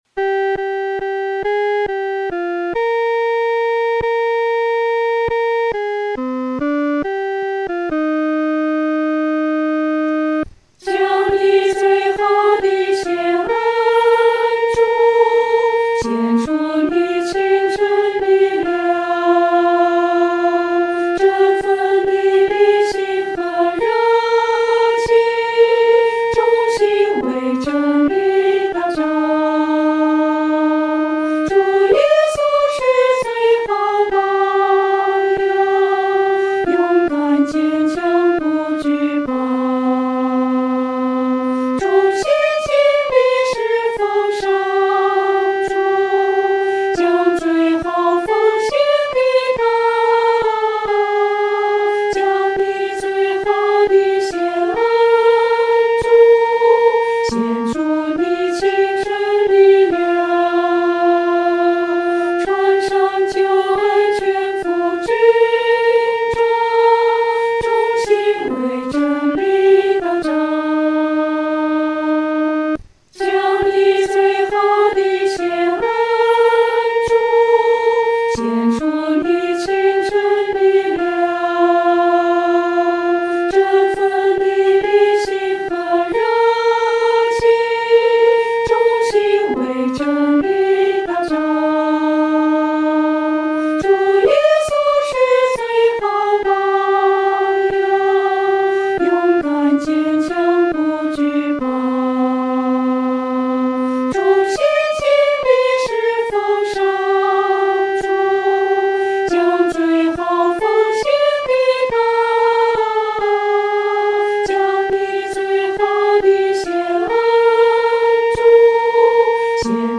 合唱
女高
本首圣诗由网上圣诗班 (南京）录制
唱时速度可以流动一些，不宜拖沓。